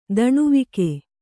♪ daṇuvike